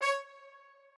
strings1_27.ogg